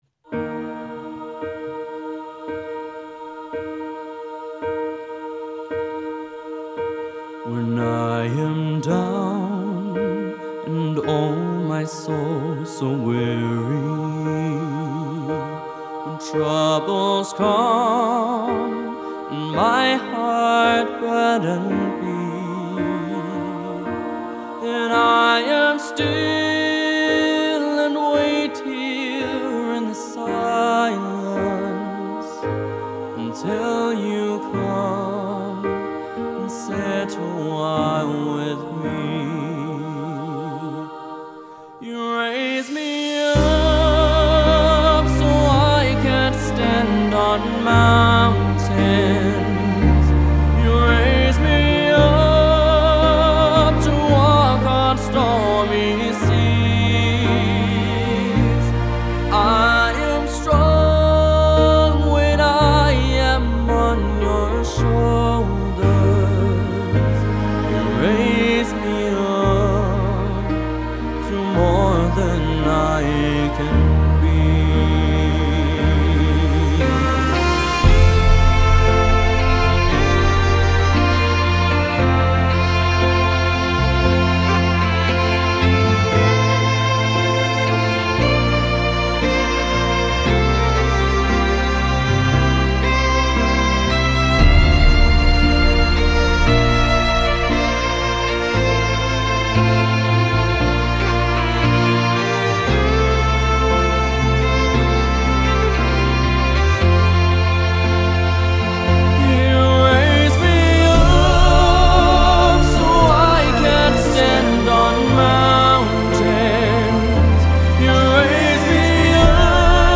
A Musician, Singer/Pianist with Talent Beyond His Years